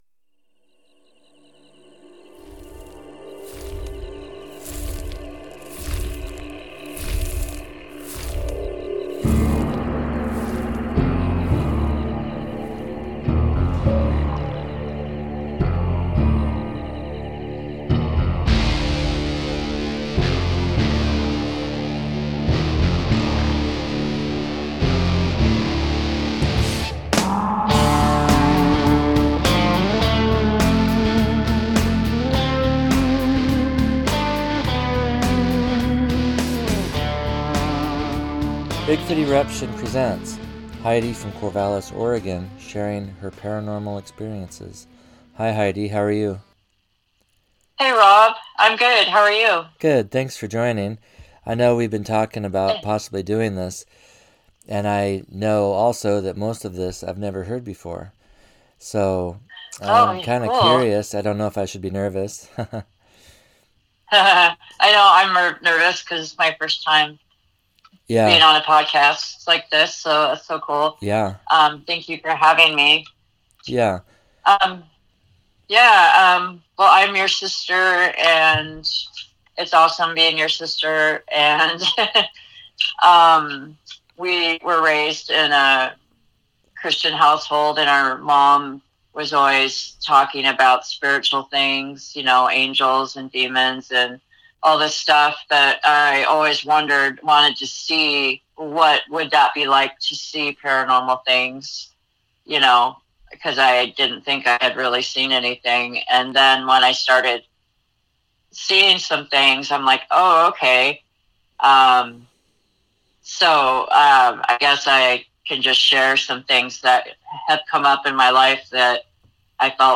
We ask, are these things paranormal or supernatural and what is the difference? Note: We have agreed to re-record this episode as we now have better equipment; however, with new equipment